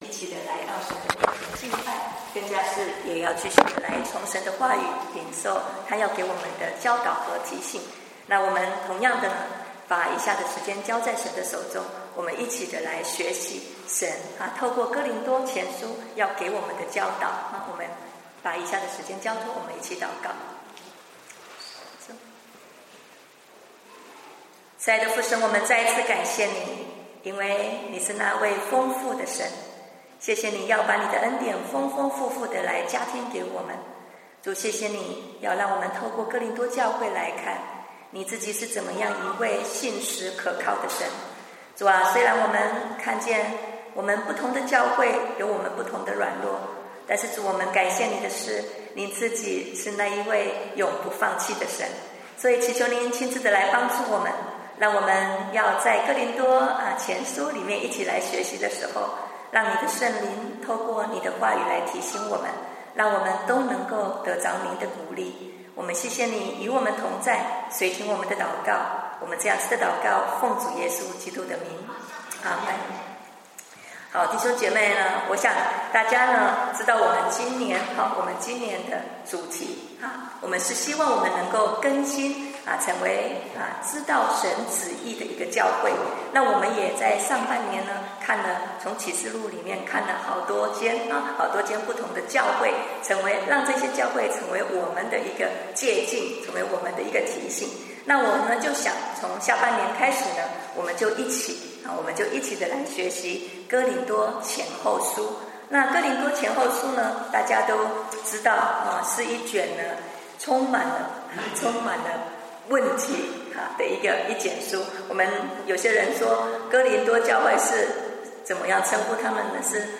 主日讲道音频